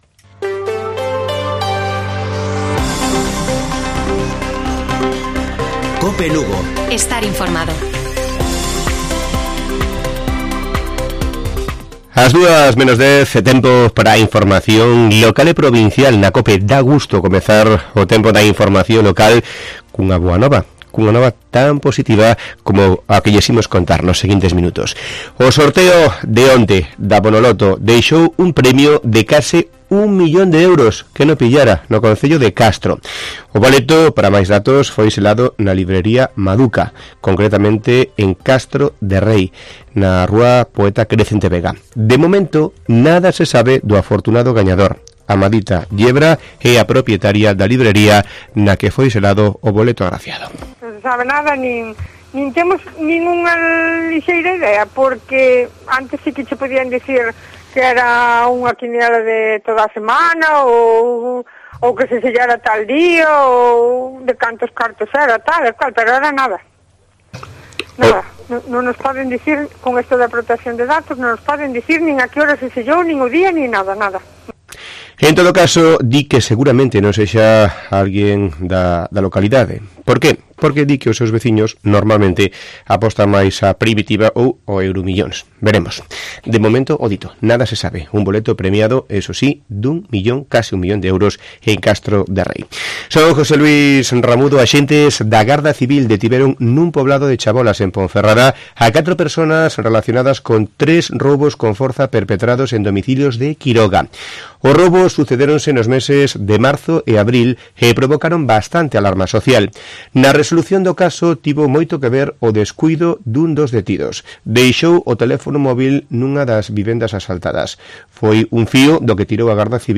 Informativo Mediodía de Cope Lugo. 4 DE OCTUBRE. 13:50 horas